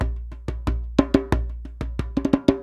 90 JEMBE6.wav